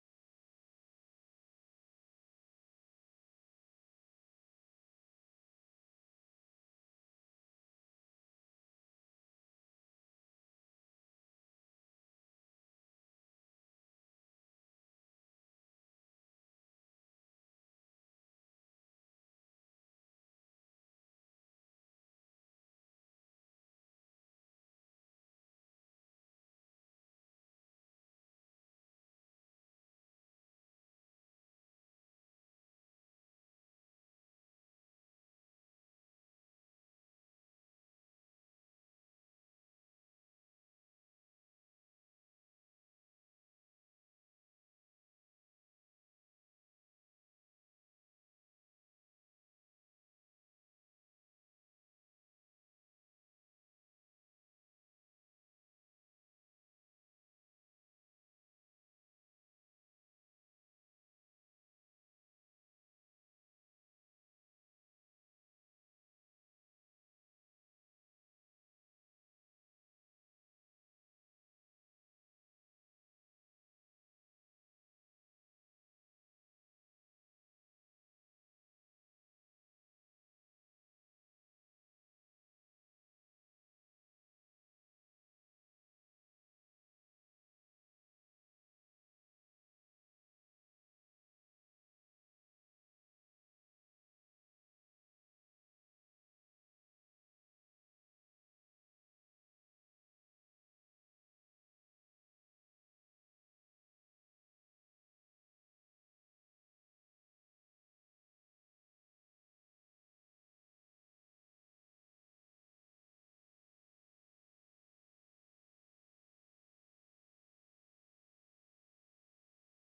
Cours de Pensée Juive sur la Paracha Mikets
Cours-de-Pensee-Juive-sur-la-Paracha-Mikets.mp3